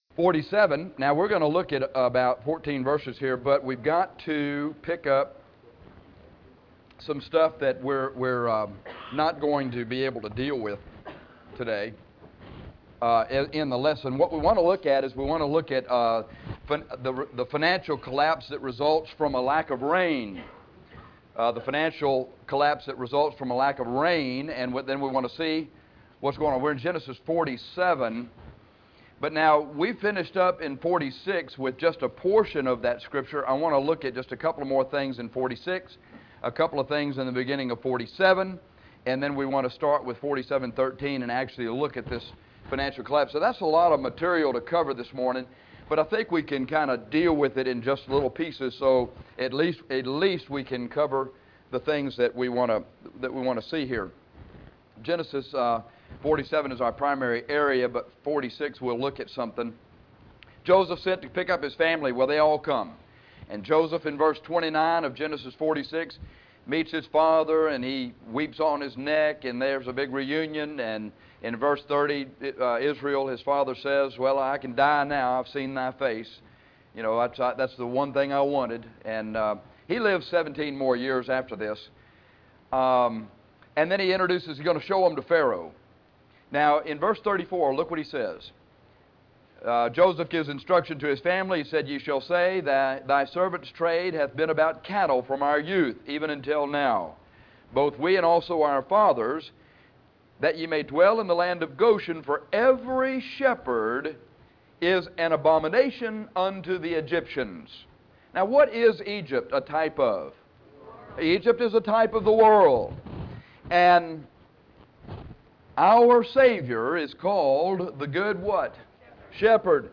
This lesson studies the progression of governmental control in Egypt, in Genesis 47:13-26. We also look at Jacob's regrets in life without God.